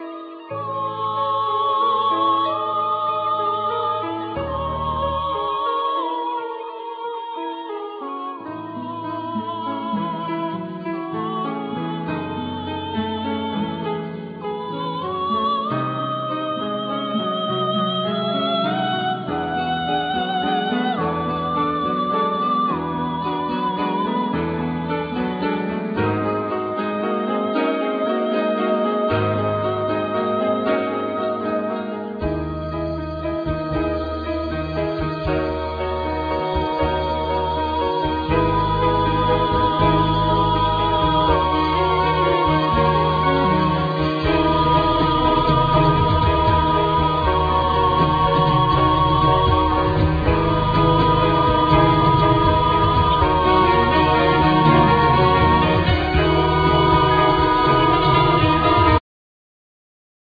Flute,EW5
Guitars
Cello
Keyboards,Bass,Percussions
Voices